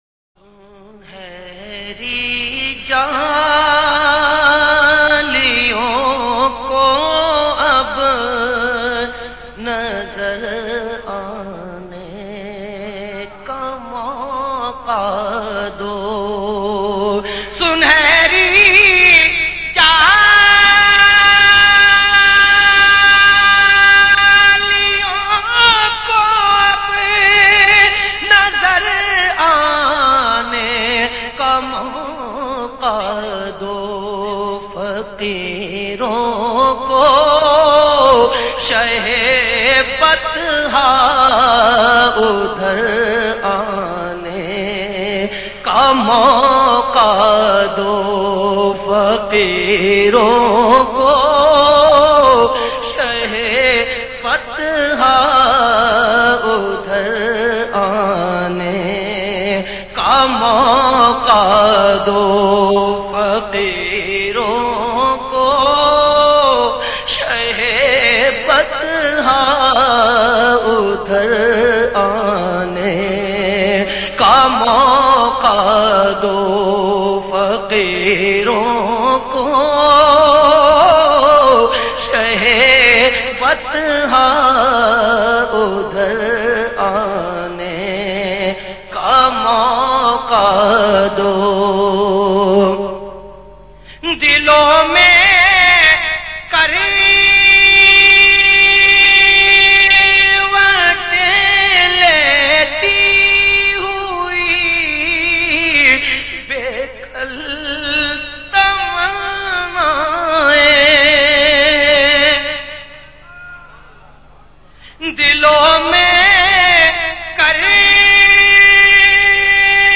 Lyrics